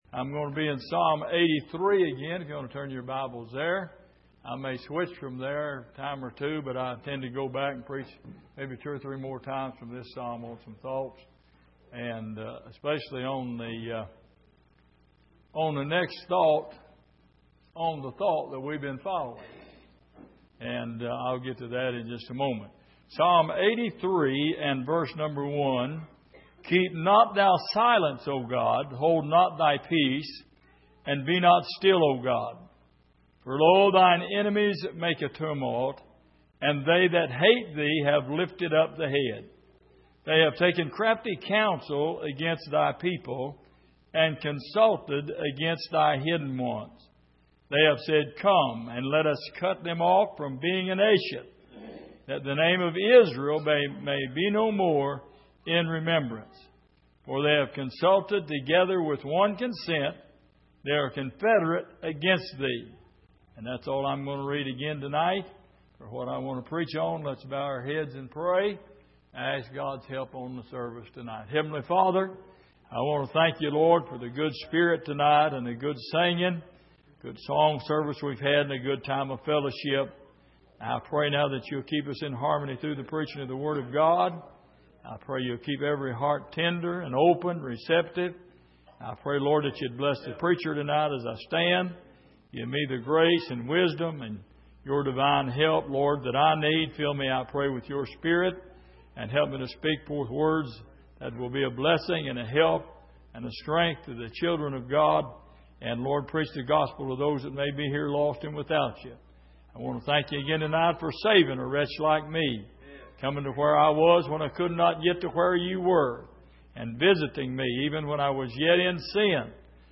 Exposition of the Psalms Passage: Psalm 83:1-5 Service: Midweek